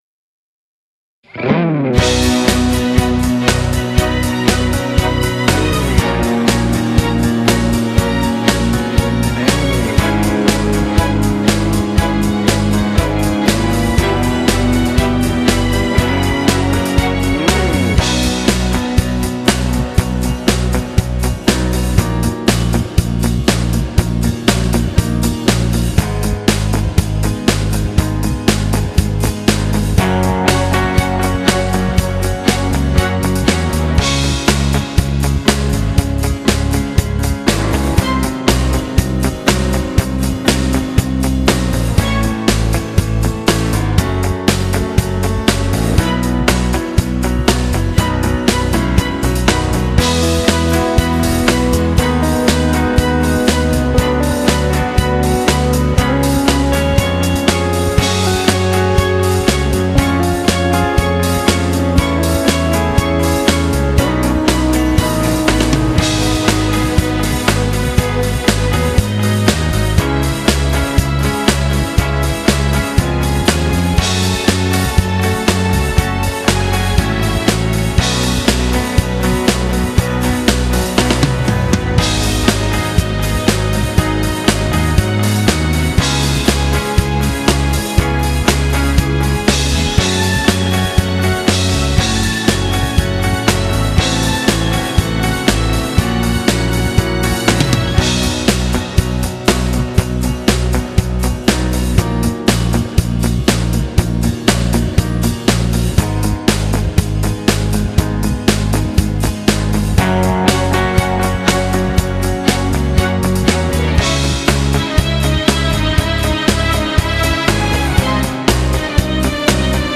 Genere: Beguine
Scarica la Base Mp3 (3,67 MB)